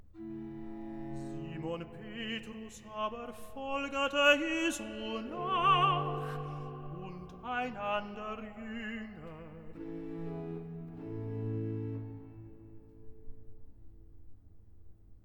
Evangelist